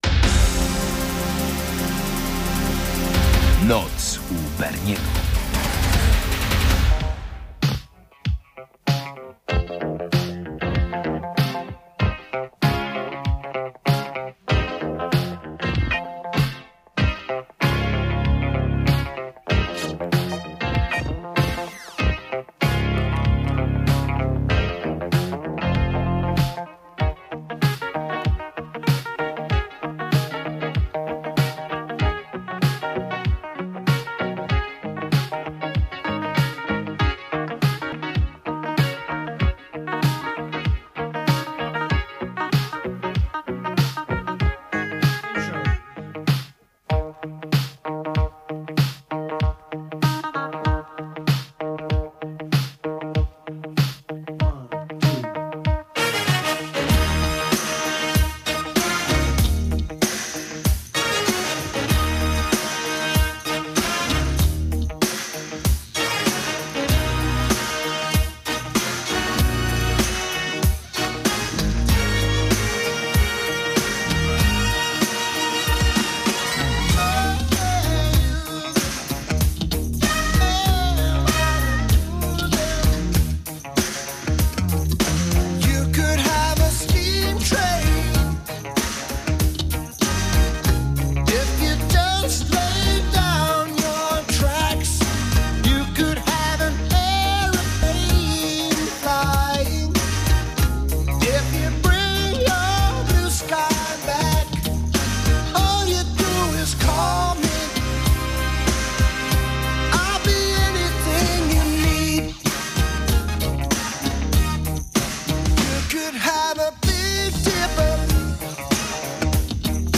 Dominujący gatunek: remiksy i maxi-single